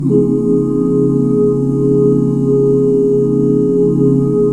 DBMAJ7 OOO-R.wav